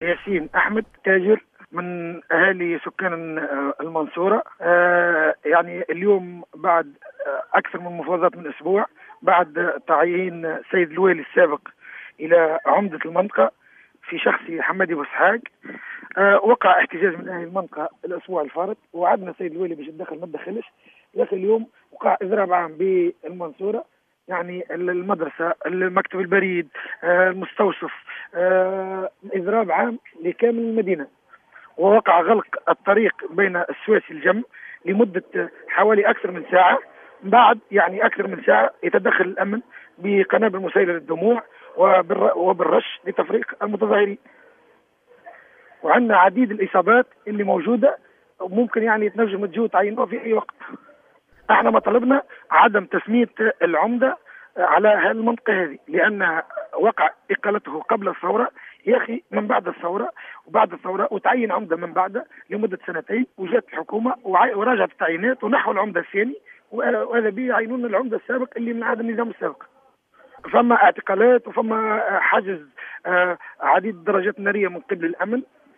ووصف أحد المحتجين في تصريح ل"الجوهرة أف أم" احتجاجاتهم ب"المشروعة"، مشيرا إلى أن الوحدات الأمنية المتواجدة على عين المكان تدخلت بشكل عنيف باستخدام الغاز المسيل للدموع لتفريق المحتجين وإيقاف عدد منهم،وفق تعبيره.